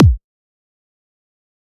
EDM Kick 47.wav